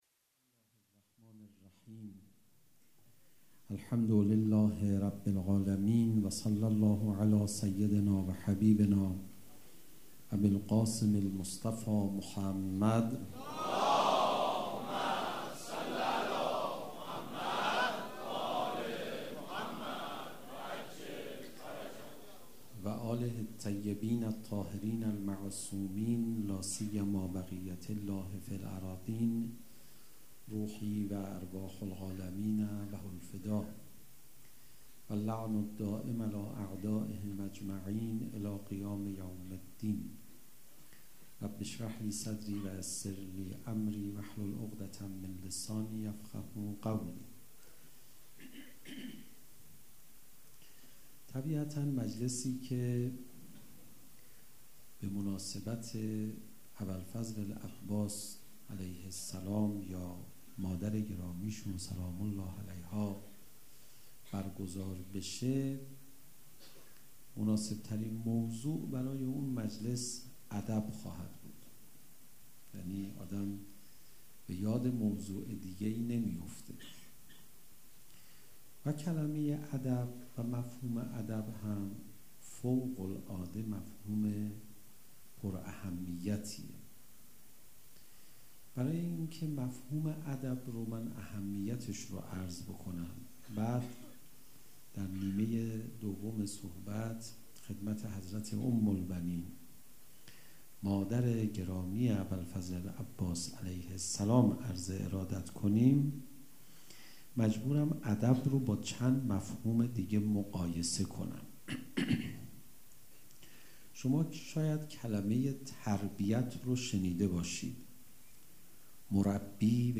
سخنرانی
مراسم شهادت حضرت ام البنین سلام الله علیها 1شنبه 21اسفند ۱۳۹۵ هیأت ریحانة الحسین سلام الله علیها
سخنـــران حجت الاسلام علیرضا پناهیان